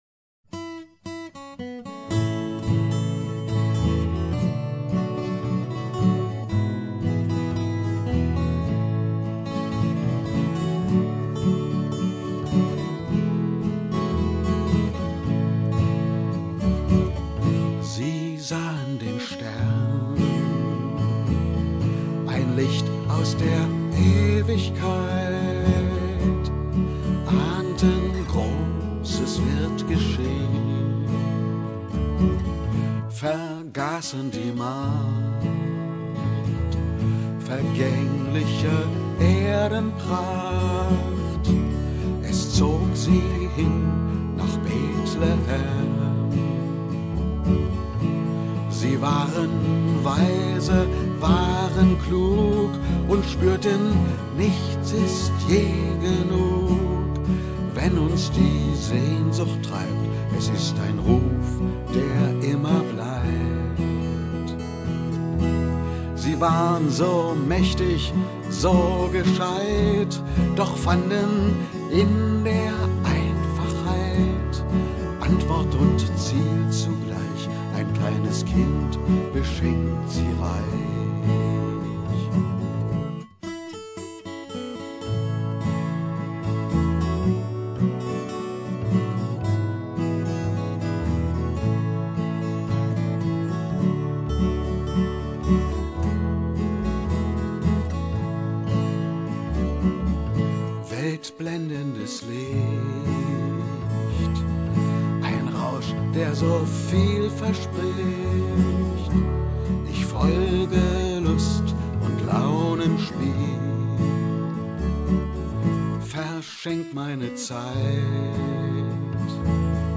(mit Streichersatz Stern über Bethlehem)Zu Weihnachten 2011